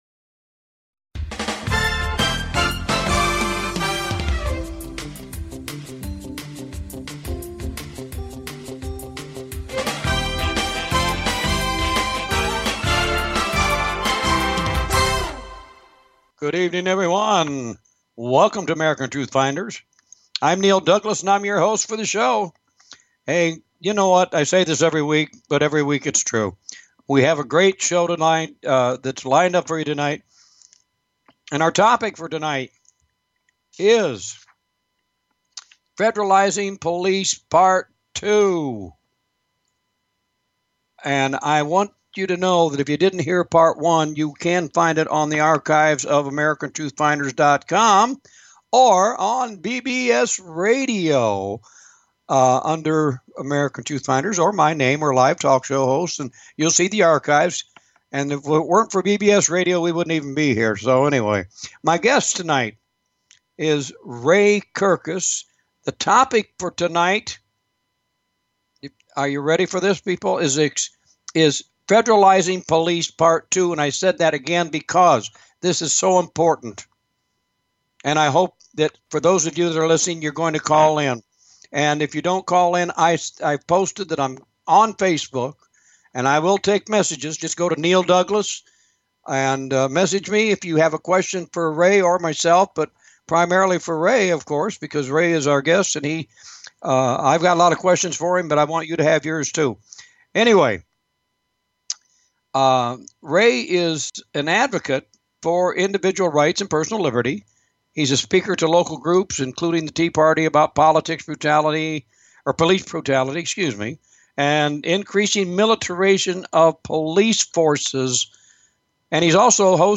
with guest